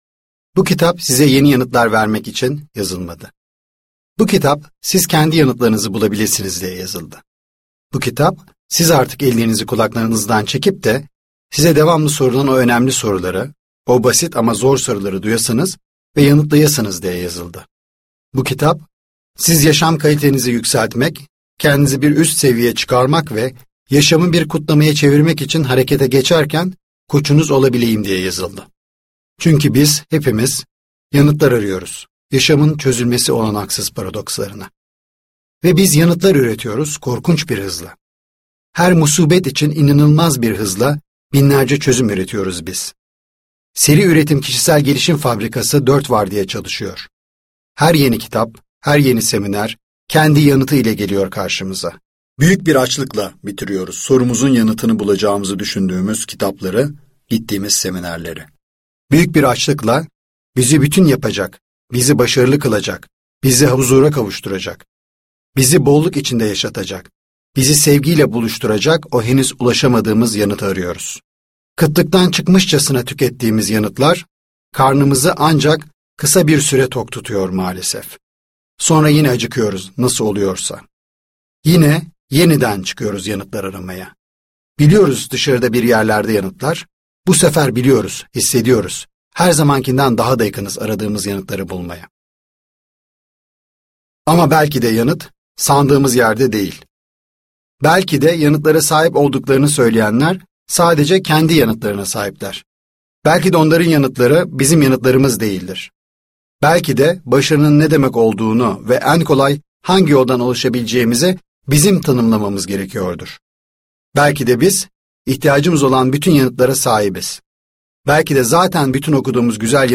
Cesur Sorular - Seslenen Kitap
Seslendiren